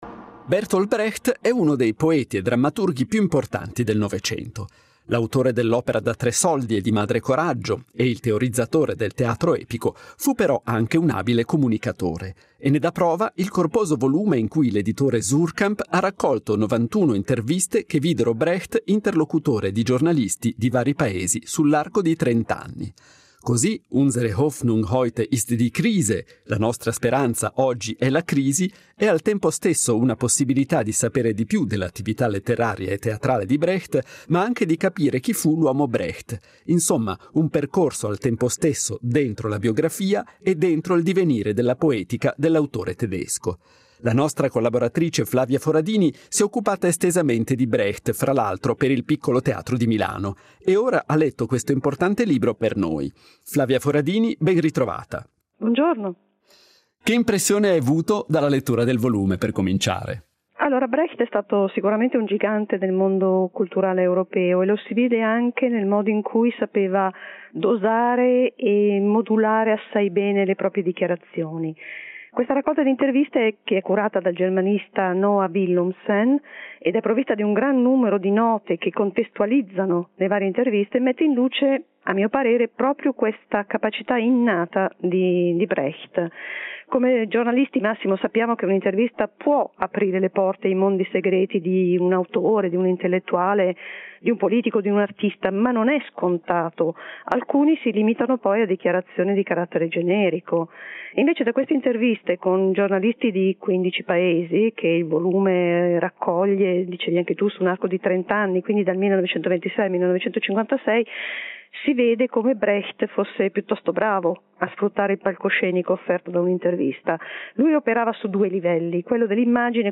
Intervista integrale.